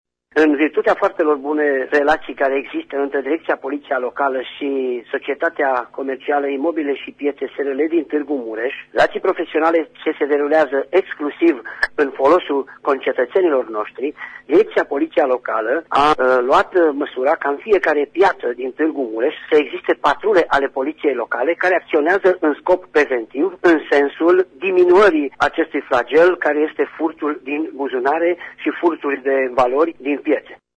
Astfel, în fiecare piaţă va fi prezentă câte o patrulă de poliţişti locali, a precizat şeful Poliţiei Locale Tîrgu-Mureş, Valentin Bretfelean: